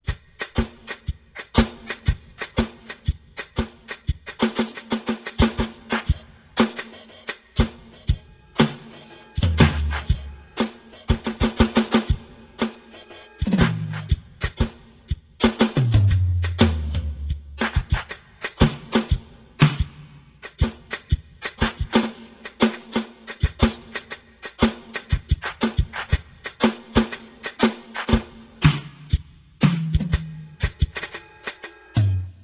Sound: (20") – Rock Beat.